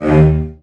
CELLOS.FN2.1.wav